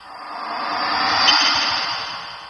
Night Rider - Sweep FX.wav